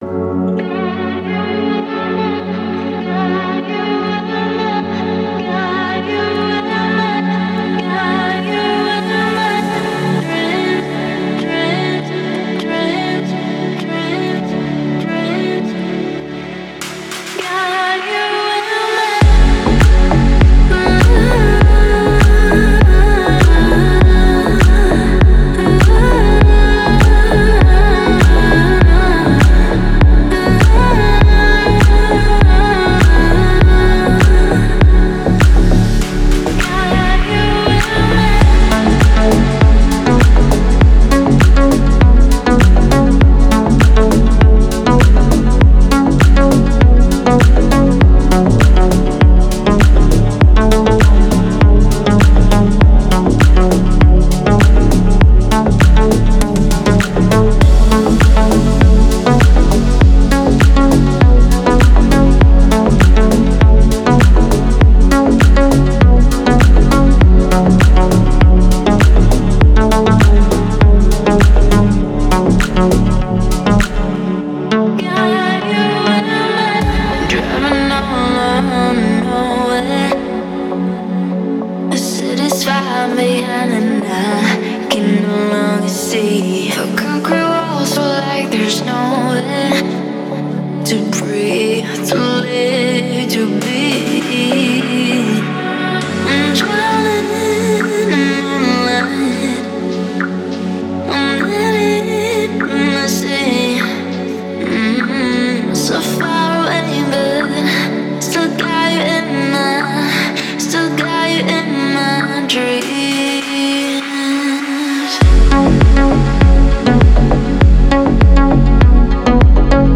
Deep House музыка
дип хаус треки